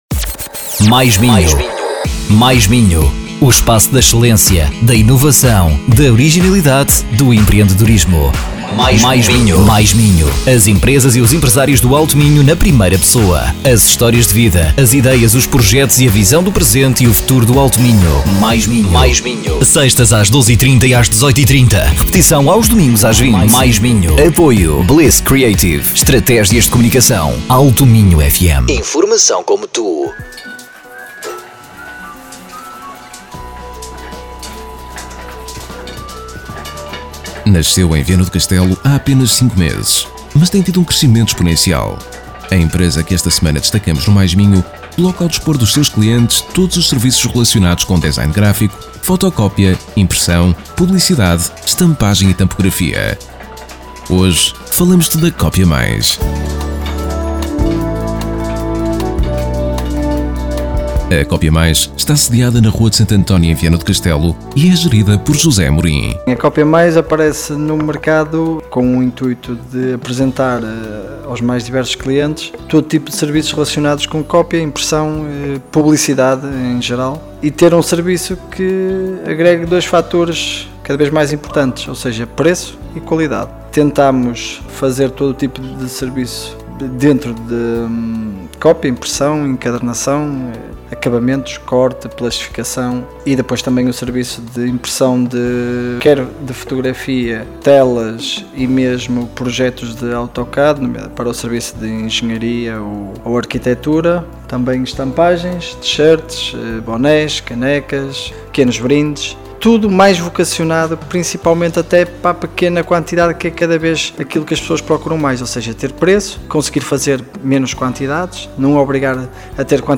Com tantos e diversos serviços que a Copia+ presta ao publico, a forma ideal de darmos a conhecer este espaço foi fazendo uma “tour” pelas instalações da empresa, que concilia no mesmo local a oficina e a zona de atendimento (ouvir áudio).